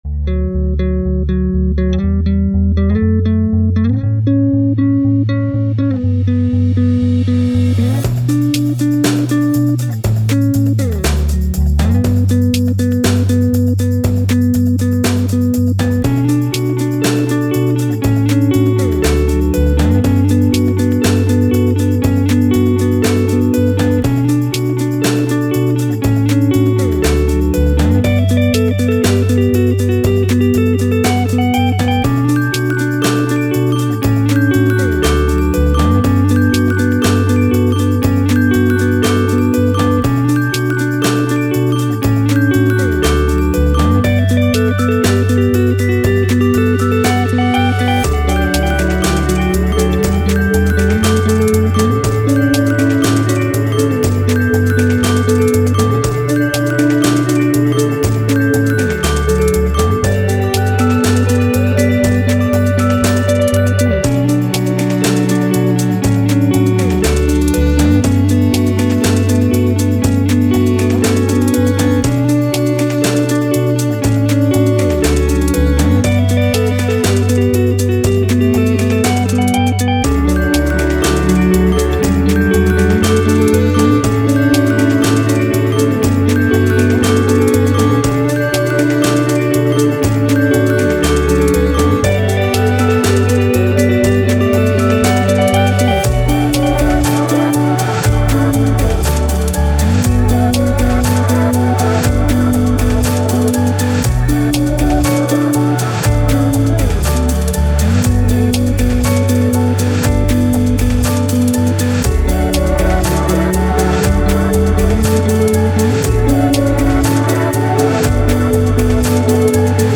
Indie, Downtempo, Thoughtful, Guitars, Journey